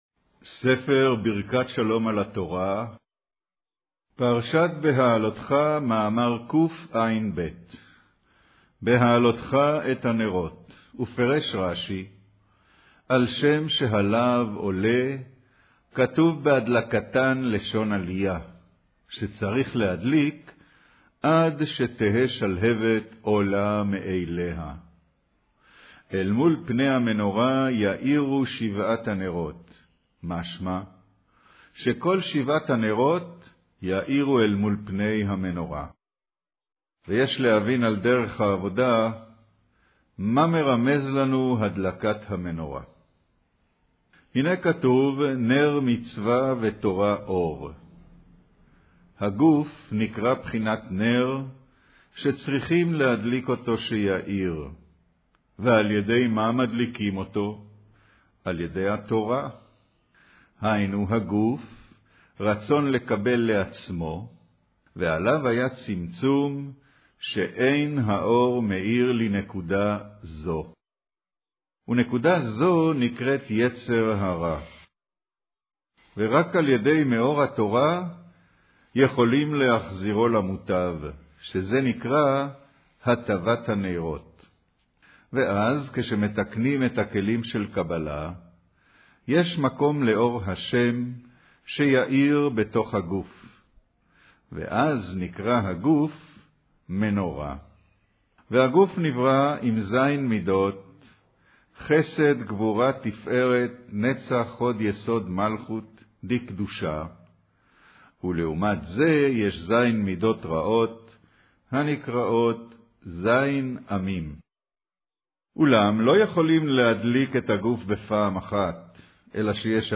קריינות פרשת בהעלותך